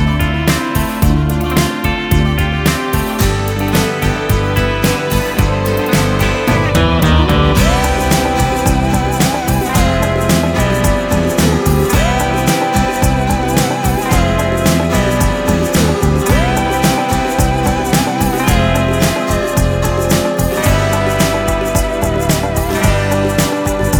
no Backing Vocals Duets 4:08 Buy £1.50